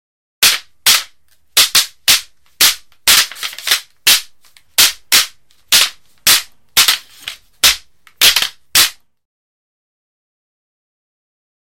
Звуки бамбука
Звук тактических ударов в кэндо: боевое искусство